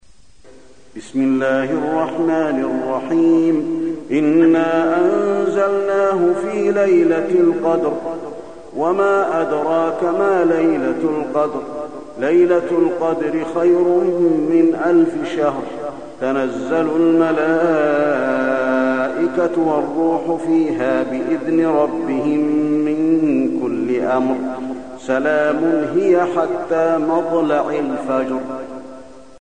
المكان: المسجد النبوي القدر